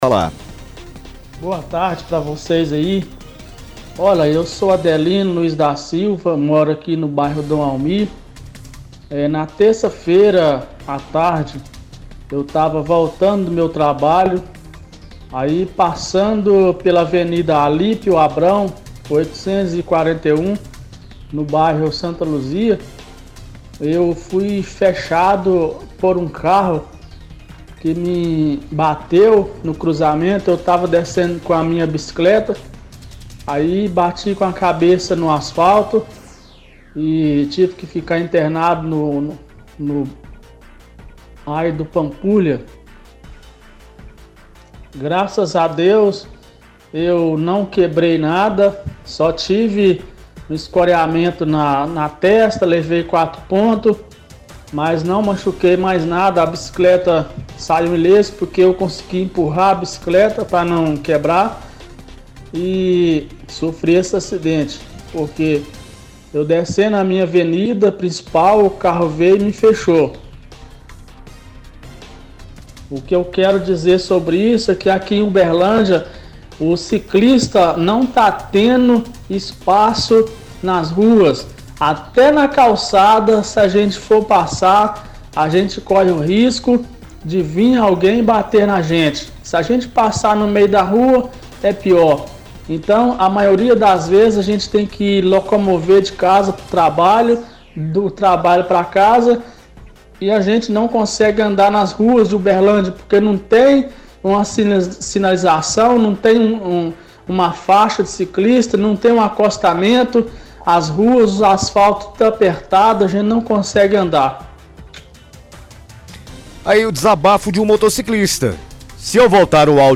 Ligação Ouvintes – Ruas de Uberlândia